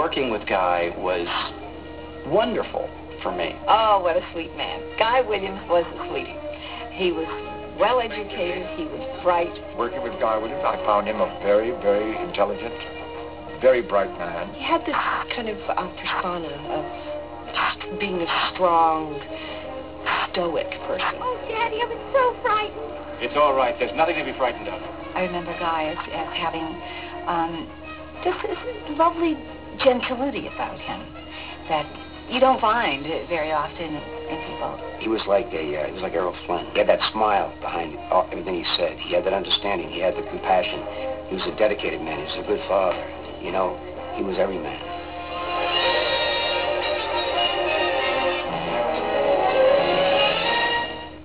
Click here to listen to the Lost in Space cast talk about Guy.